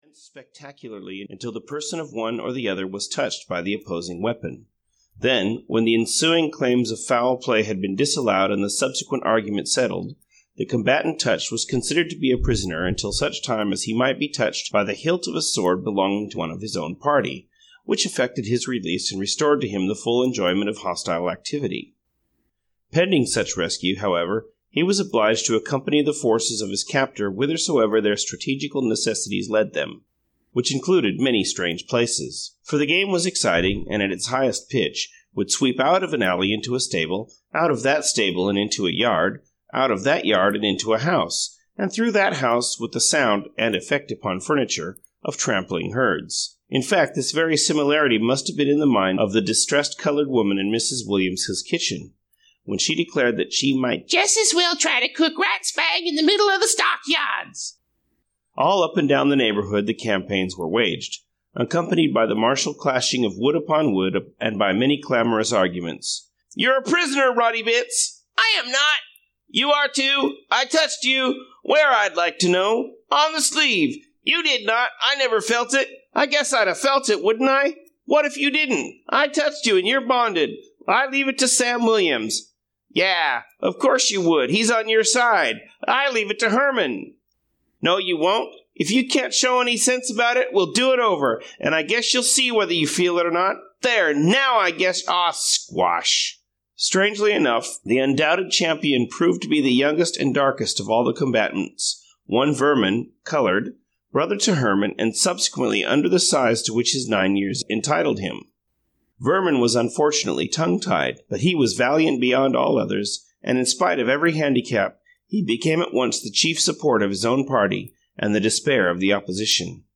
Penrod and Sam (EN) audiokniha
Ukázka z knihy